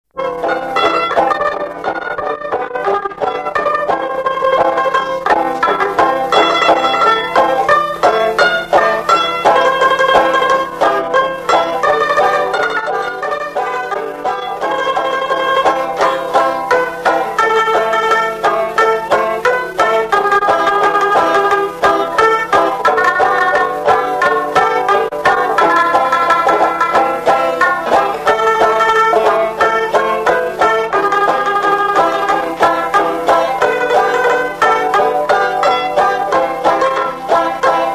Instrumental
Pièce musicale inédite